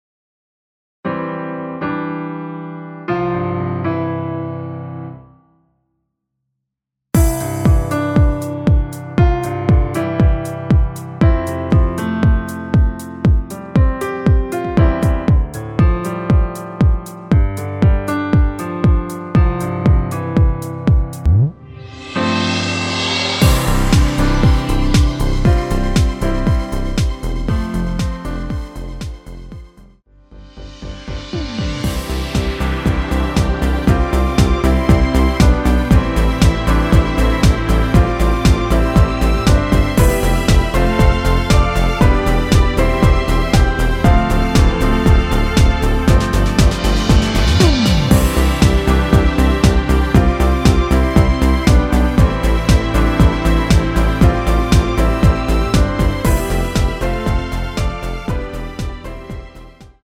전주가 없는 곡이라 2마디 전주 만들어 놓았습니다.
랩부분과 마지막 랩부분은 후렴부분도 없습니다.
앞부분30초, 뒷부분30초씩 편집해서 올려 드리고 있습니다.
중간에 음이 끈어지고 다시 나오는 이유는